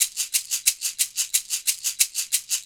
Shaker 08.wav